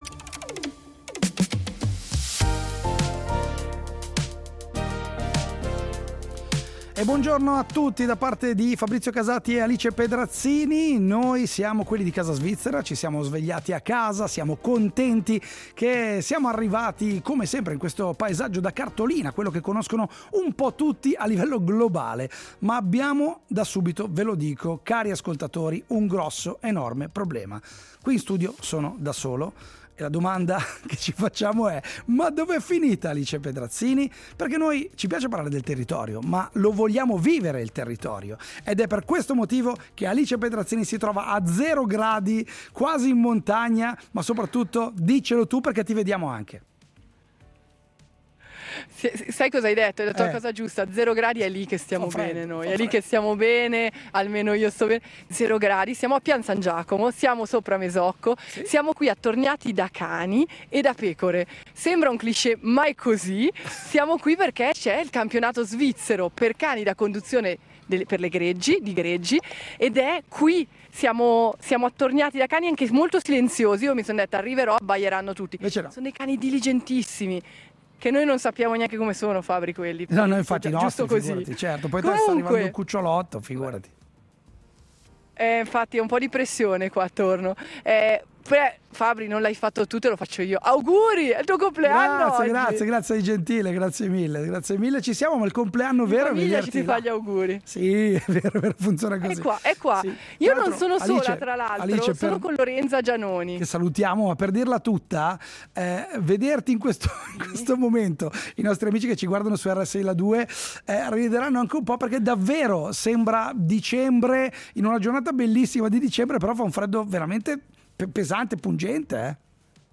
Casa Svizzera in diretta da Pian San Giacomo!